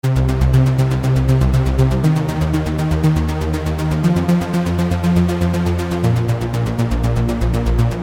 120 BPM Electronic Synth Loops & Melodic Sequences
120-bpm-analog-synth-loop.mp3